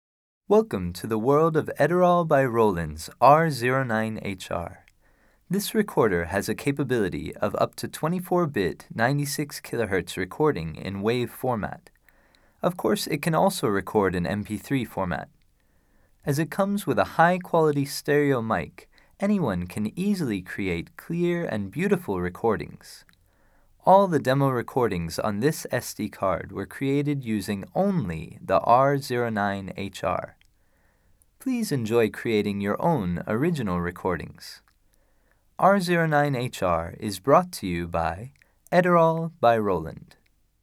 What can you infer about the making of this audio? Holy cow! This is from the internal mics... But they did cheat a little at the start, fade, in...